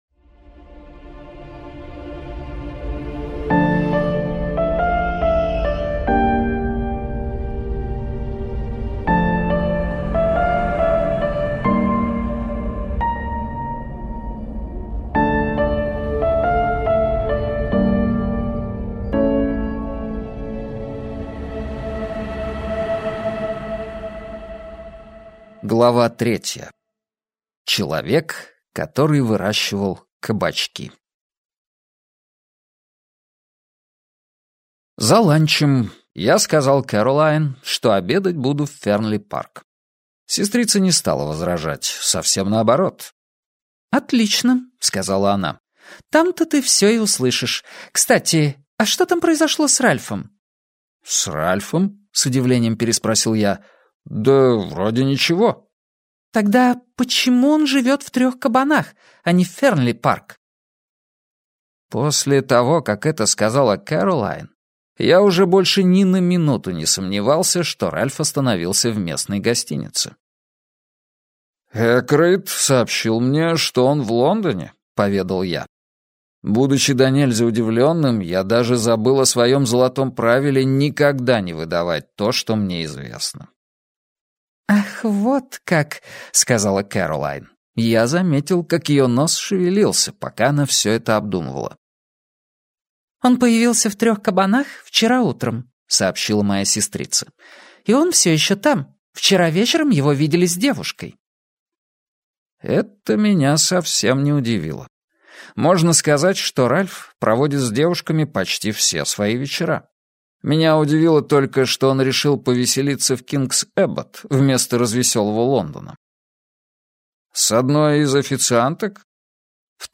Аудиокнига Убийство Роджера Экройда - купить, скачать и слушать онлайн | КнигоПоиск